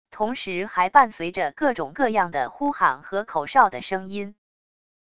同时还伴随着各种各样的呼喊和口哨的声音wav音频